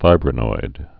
(fībrə-noid, fĭbrə-)